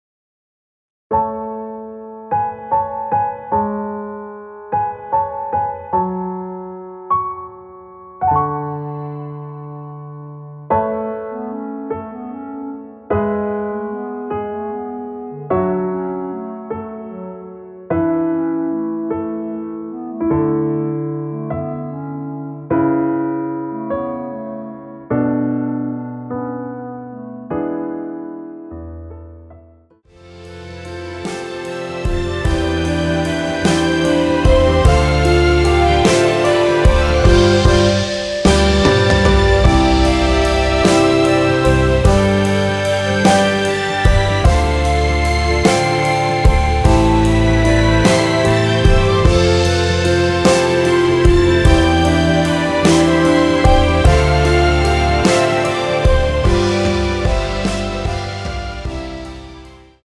원키에서(-8)내린 멜로디 포함된 MR입니다.(미리듣기 참조)
앞부분30초, 뒷부분30초씩 편집해서 올려 드리고 있습니다.
중간에 음이 끈어지고 다시 나오는 이유는